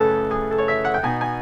keys_15.wav